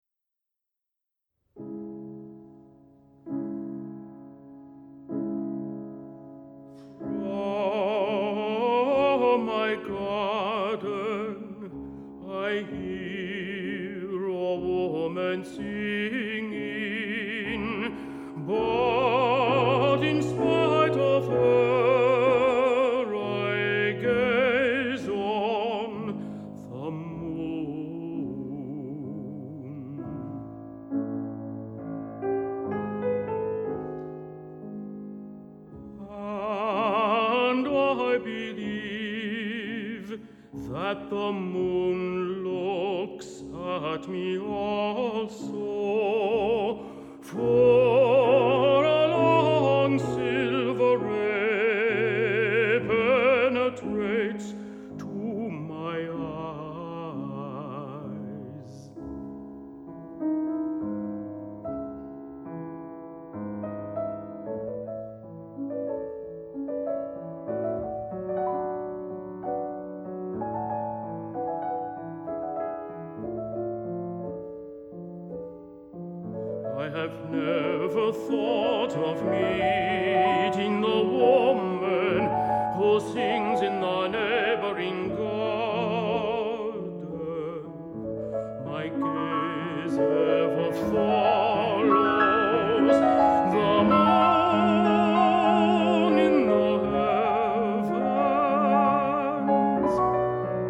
★ 原創且具有清新氣息之歌謠，充分表現出充滿磁性、盪氣迴腸的男高音美聲！
演唱多首受到晚期浪漫主義影響的作品，在透明的錄音中，傳達出人聲最美麗的境界。